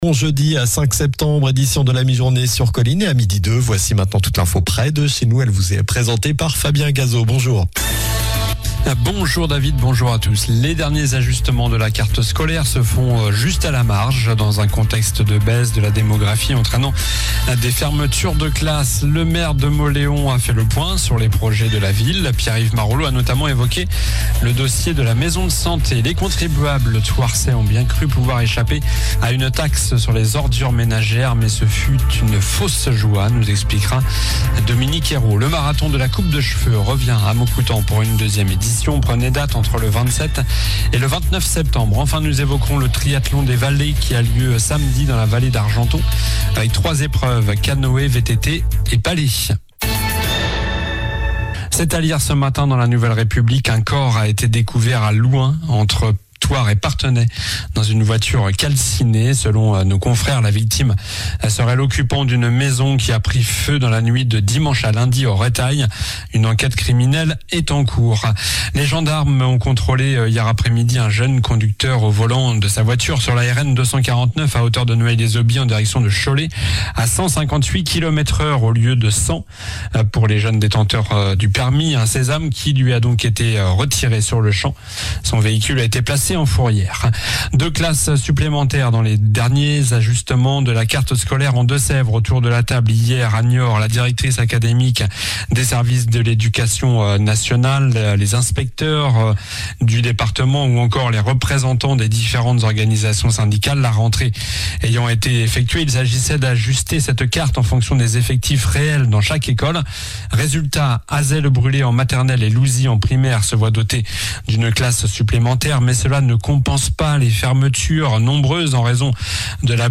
Journal du jeudi 05 septembre (midi)